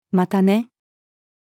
bye-female.mp3